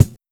Track 02 - Kick OS 02.wav